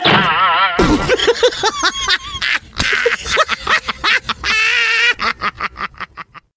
Naloko na Sounds Effect Of People Laughing